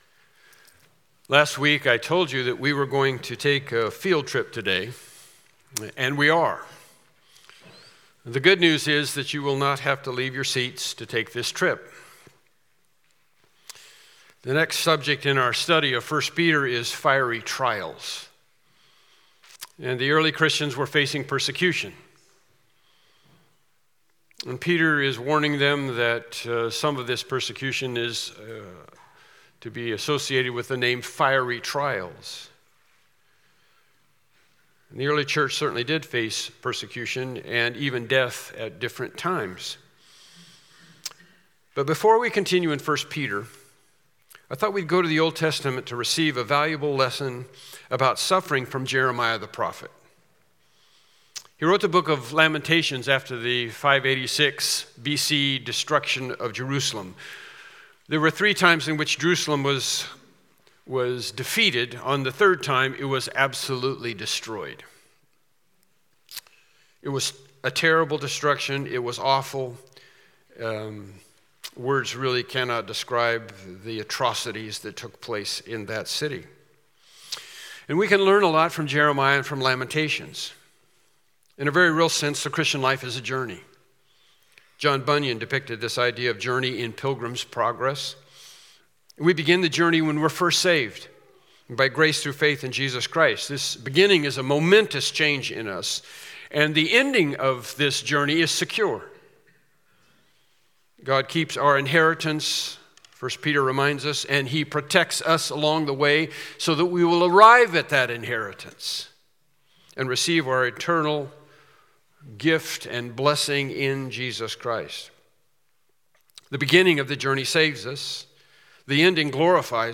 Lamentations Service Type: Morning Worship Service Topics